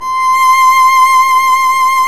Index of /90_sSampleCDs/Roland L-CD702/VOL-1/STR_Violin 1-3vb/STR_Vln1 % + dyn